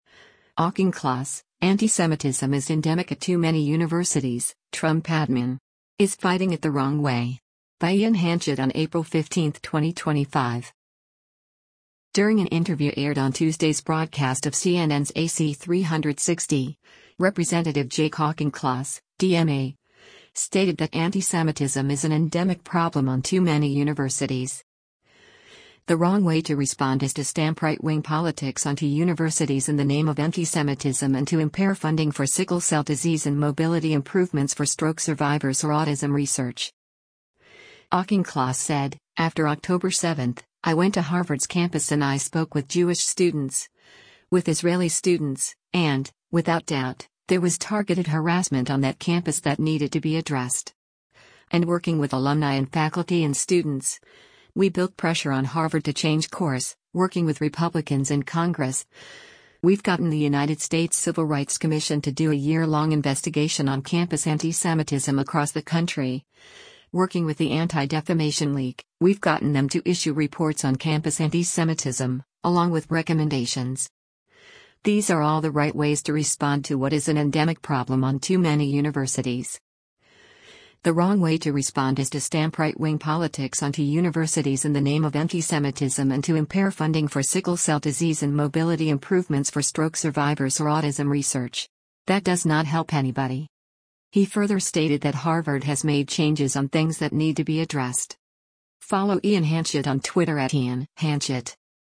During an interview aired on Tuesday’s broadcast of CNN’s “AC360,” Rep. Jake Auchincloss (D-MA) stated that antisemitism is “an endemic problem on too many universities. The wrong way to respond is to stamp right-wing politics onto universities in the name of antisemitism and to impair funding for sickle cell disease and mobility improvements for stroke survivors or autism research.”